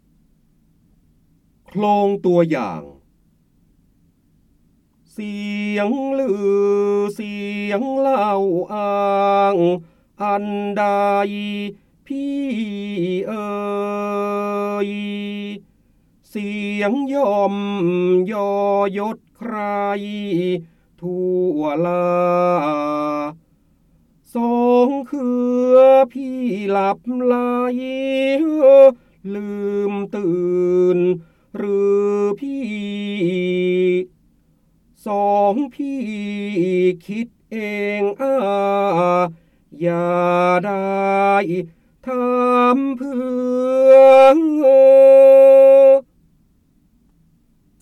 เสียงบรรยายจากหนังสือ จินดามณี (พระโหราธิบดี) โคลงตัวอย่าง
คำสำคัญ : ร้อยแก้ว, จินดามณี, พระโหราธิบดี, ร้อยกรอง, การอ่านออกเสียง, พระเจ้าบรมโกศ
ลักษณะของสื่อ :   คลิปการเรียนรู้, คลิปเสียง